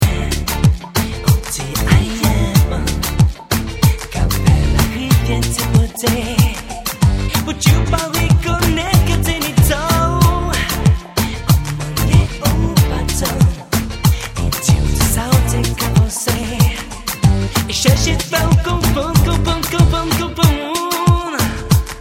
Chant
Basse
Guitares
Claviers